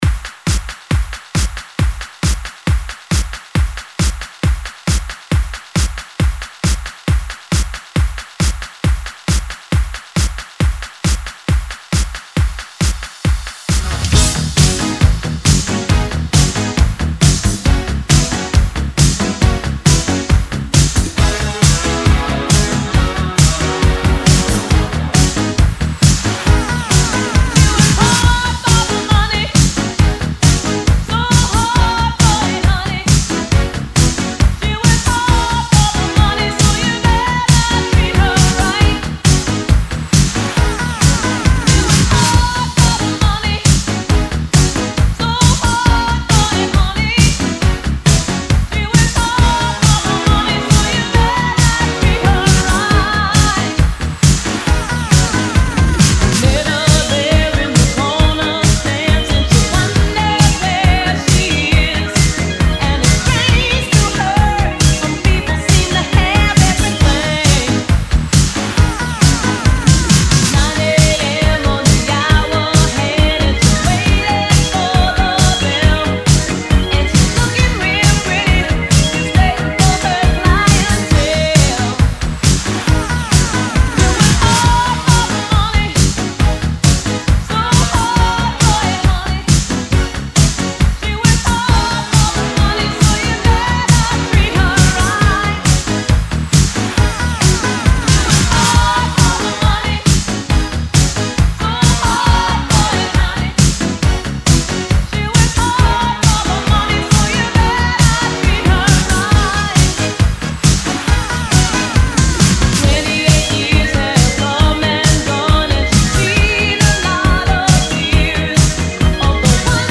82 Bpm Genre: 80's Version: Clean BPM: 82 Time